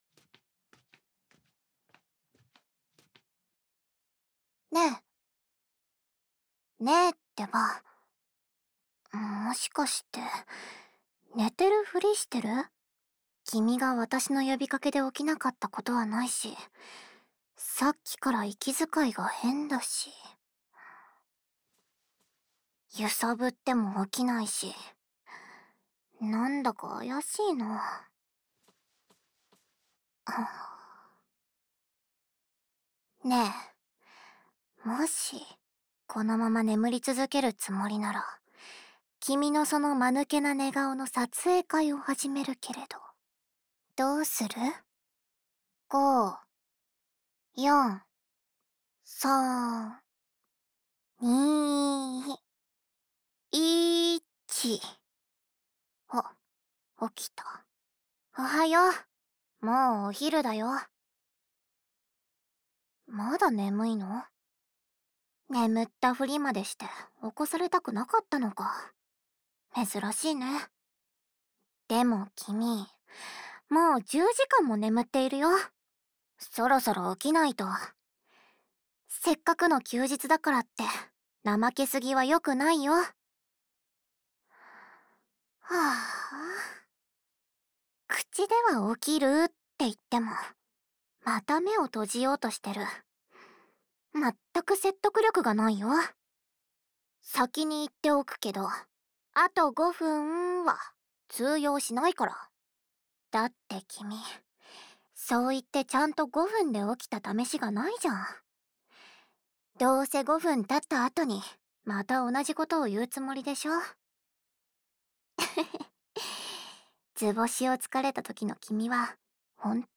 纯爱/甜蜜 温馨 治愈 掏耳 环绕音 ASMR 低语